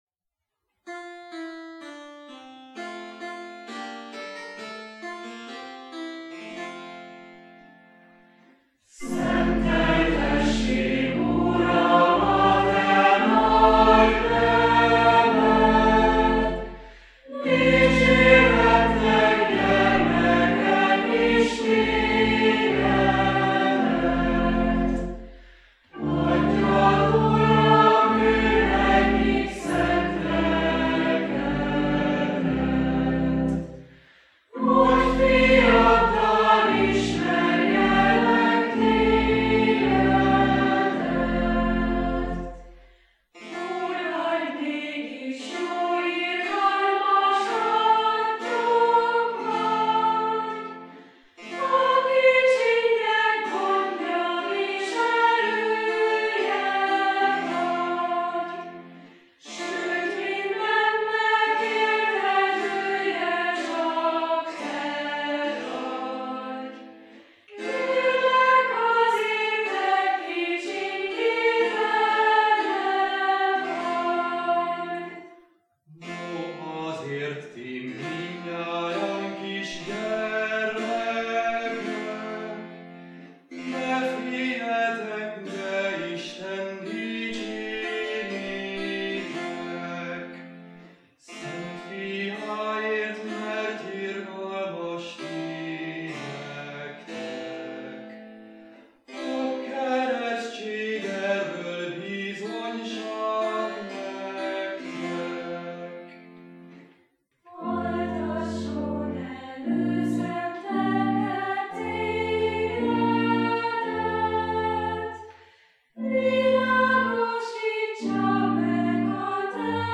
A vershez társított régi magyar zsoltárdallam (GyLK 697) alkalmasnak tűnik „rengetőnek”, egyszerű motívumokból építkezik, sorszerkezete A, B, C, B, melyben az A és C sorok tükörszerűen viszonyulnak egymáshoz.